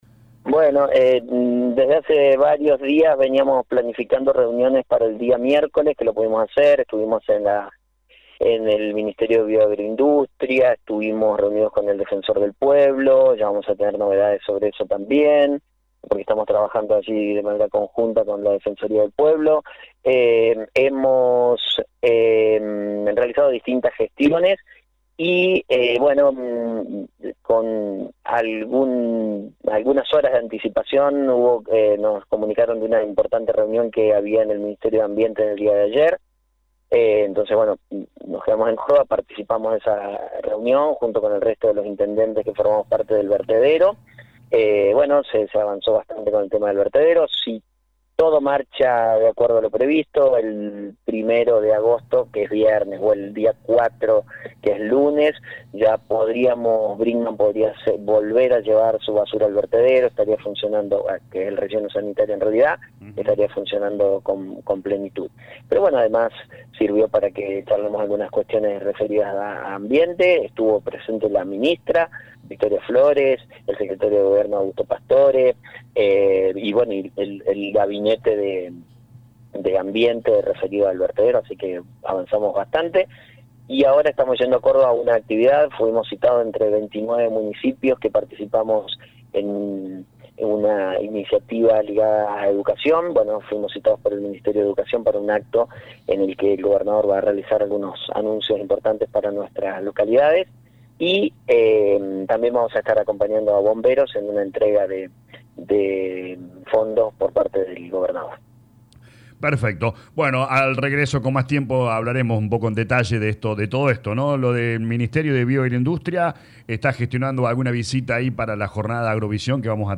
En diálogo con LA RADIO 102.9, Actis detalló que uno de los encuentros fue en la Secretaría de Ambiente, donde se avanzó en el proyecto del vertedero regional, una obra clave para el tratamiento y disposición final de residuos sólidos urbanos, que involucra a varias localidades de la región.